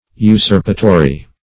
usurpatory - definition of usurpatory - synonyms, pronunciation, spelling from Free Dictionary
Search Result for " usurpatory" : The Collaborative International Dictionary of English v.0.48: Usurpatory \U*surp"a*to*ry\, a. [L. usurpatorius.]
usurpatory.mp3